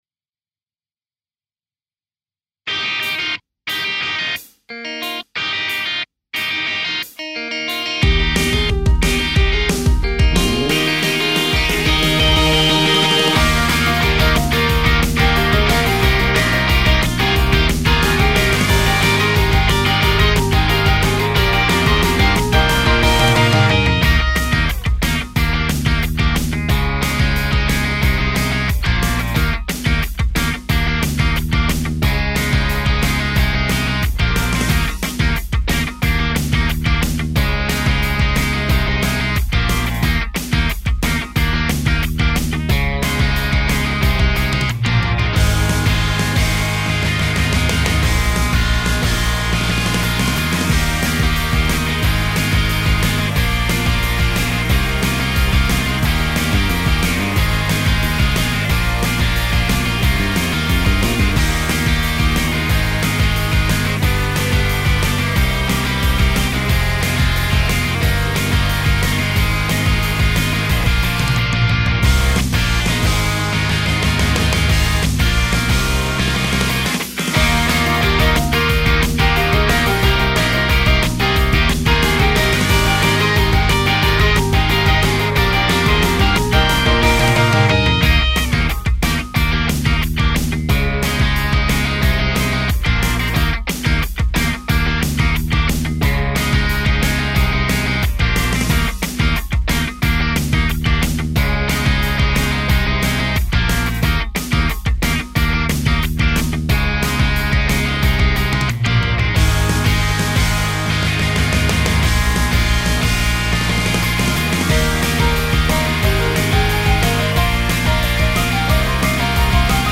伴奏＋掛声